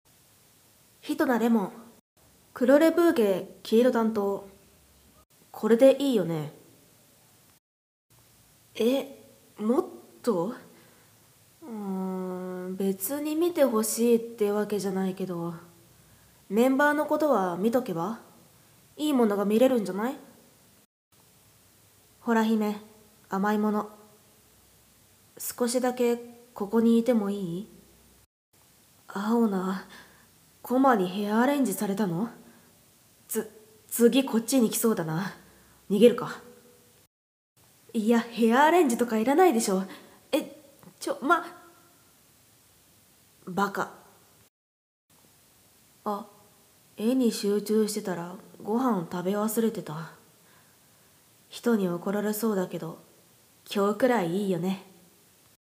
自己紹介